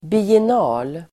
Ladda ner uttalet
biennal substantiv, biennial Uttal: [bien'a:l] Böjningar: biennalen, biennaler Definition: (konst)utställning som återkommer vartannat år ((an exhibition or the like) held every two years) Sammansättningar: konst|biennal (biennial exhibition)